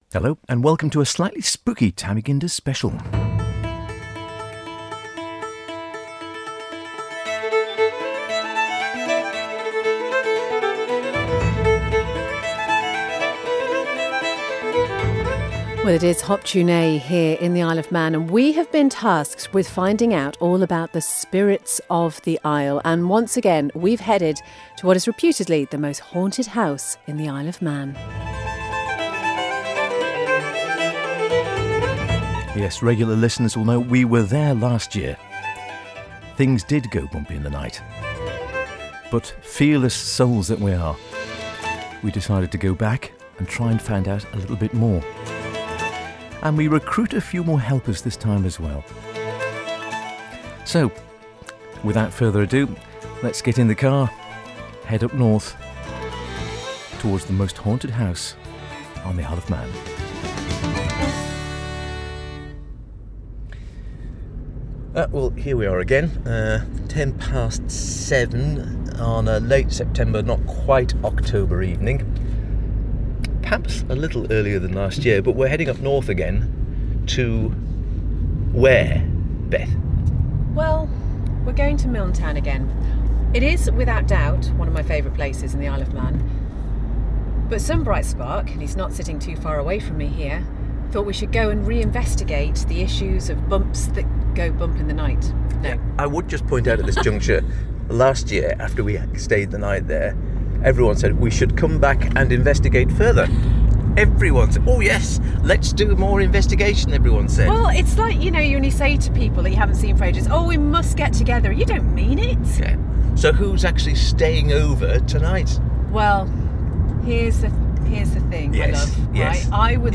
Hop Tu Naa Special from Milntown